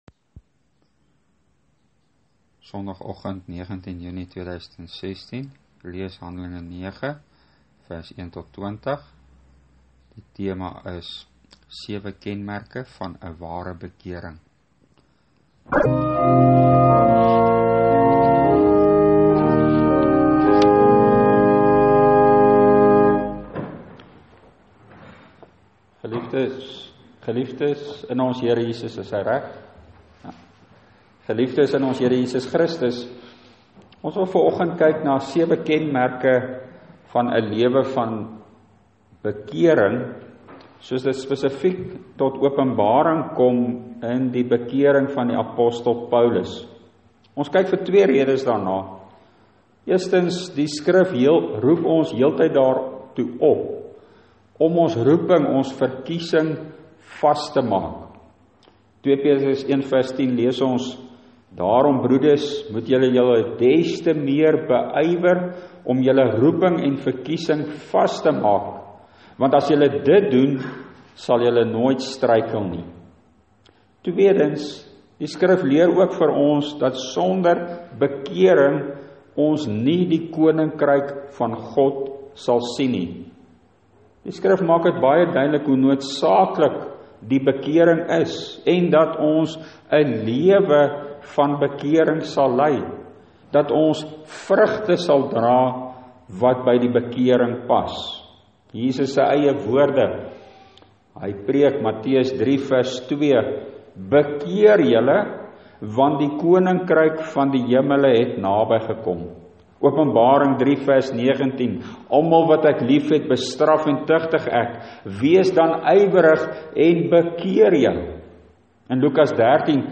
Preek: Hand.9 (Hoe lyk ‘n lewe van bekering na Christus?)
Preekopname (GK Carletonville, 2016-06-19):